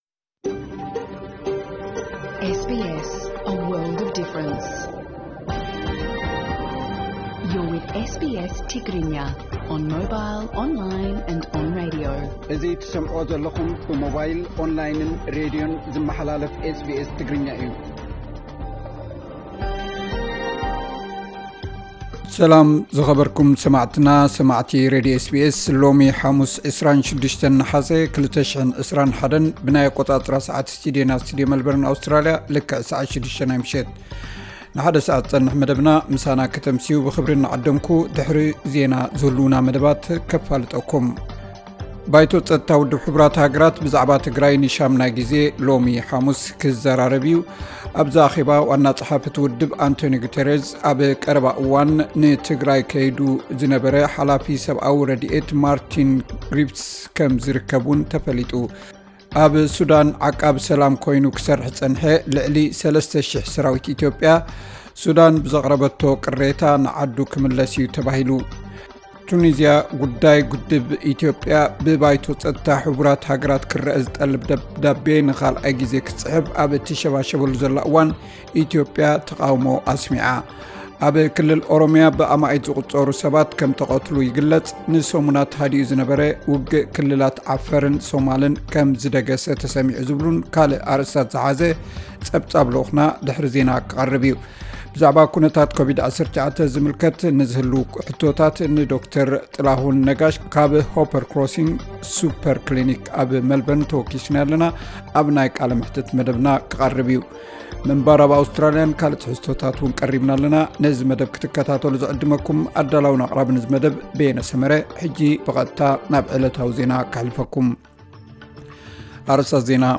ኣርእስታት ዜና፡ ** ኒው ሳውዝ ዌልስ ካብ ምጅማር እቲ ለበዳ ጀሚሩ ዝለዓለ ቑፅሪ መዓልታዊ ብምምዝጋብ 1,029 ሓደሽቲ ብኮቪድ-19 ተለኺፎም ። ** ሰራዊት ኤርትራ ናብ ምዕራብ ትግራይ ምእታዎም ንኣመሪካን ሕብረት ኣውሮጳን የሻቕሎም። *** ኣብ ዉድድራት ፓራኦሎምፒክ ቶክዮ፡ ኣውስትራሊያ ብብዝሒ መዳሊያታት ትመርሕ።